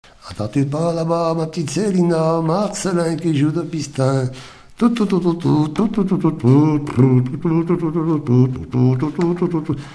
Mémoires et Patrimoines vivants - RaddO est une base de données d'archives iconographiques et sonores.
Genre brève
Pièce musicale inédite